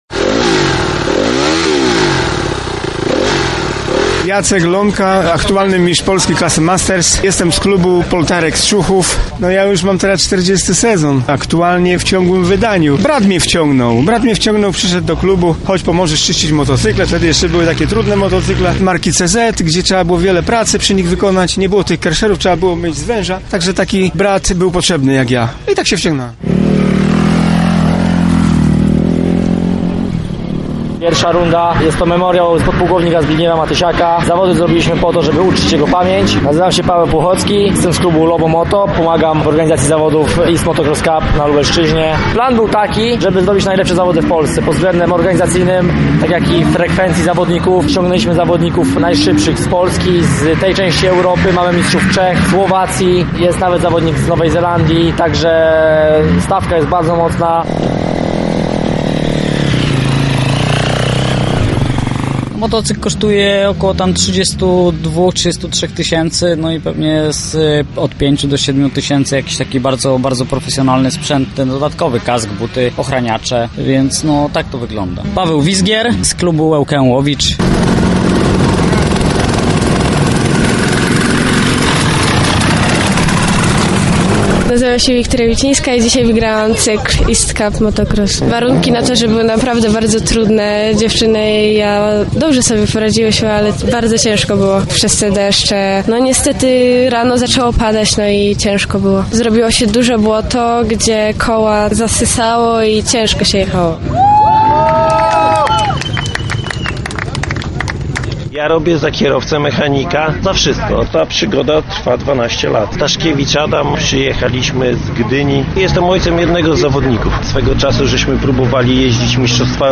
Ryk silników przy ulicy Janowskiej dobiegł końca. Za nami I runda East Motocross Cup 2019
Więcej usłyszycie w naszej relacji.